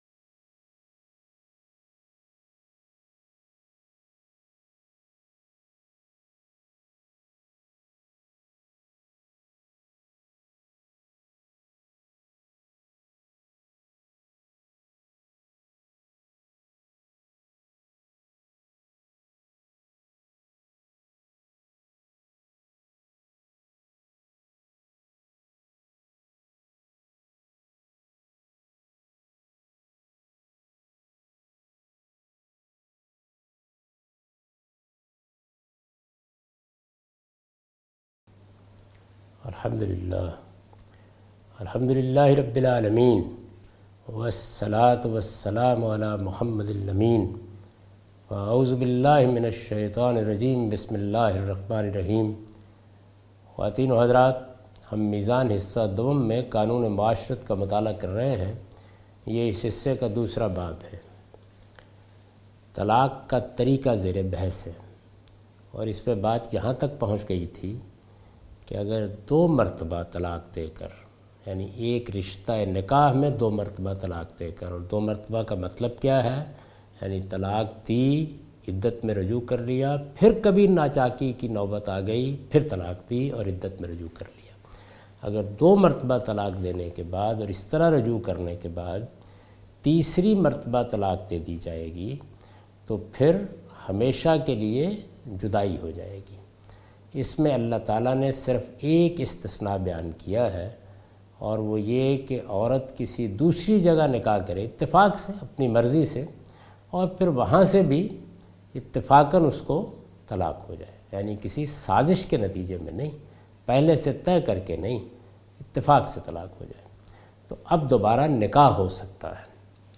A comprehensive course on Islam, wherein Javed Ahmad Ghamidi teaches his book ‘Meezan’.
In this lecture he teaches ruling of divorce and Idat in Islam.